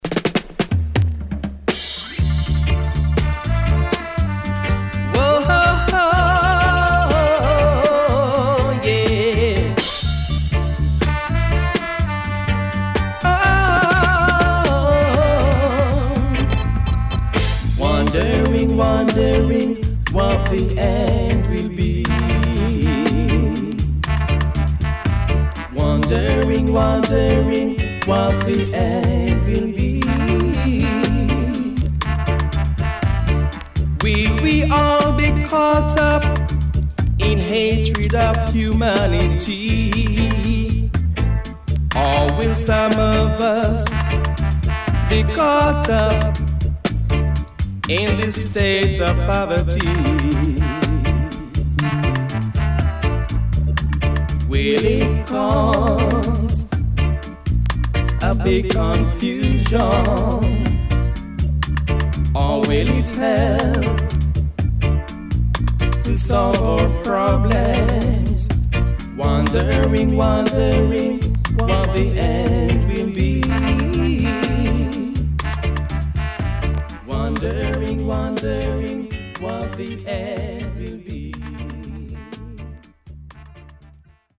83年マイナー調からGood Mediumまで良曲多数